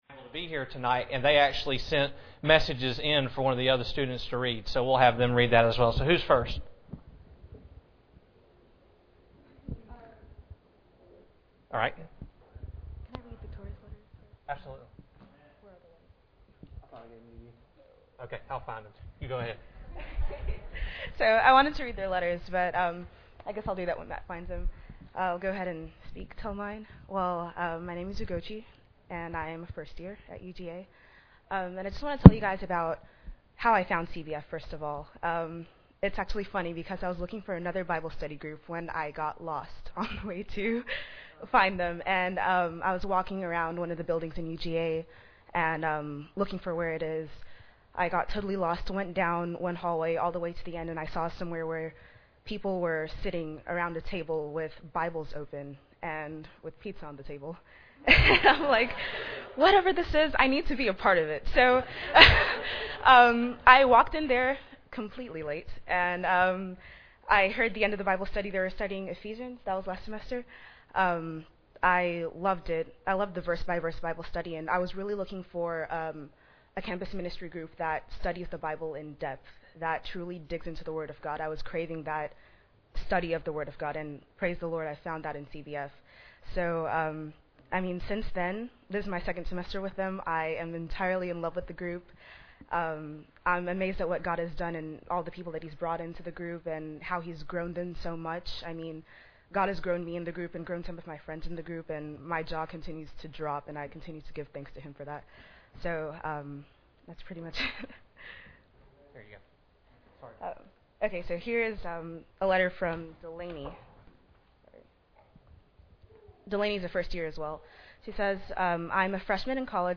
Greater Works – Jesus Continued – Crooked Creek Baptist Church
Service Type: Sunday Evening